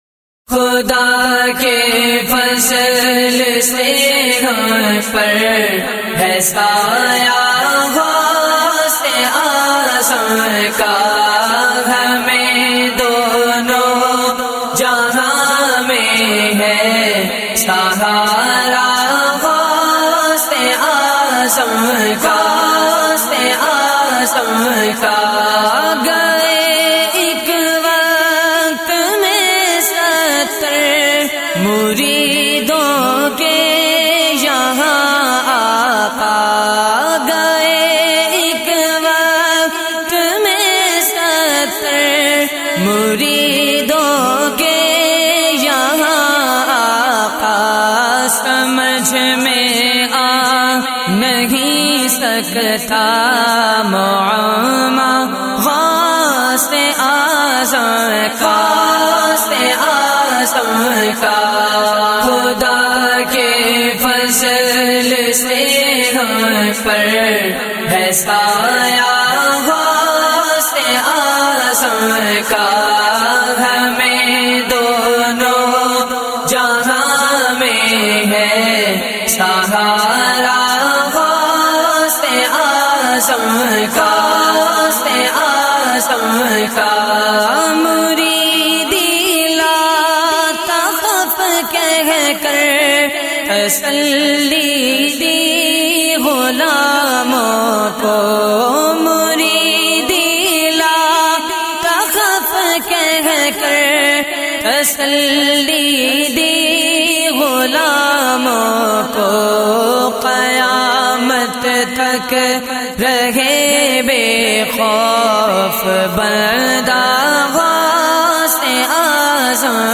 منقبت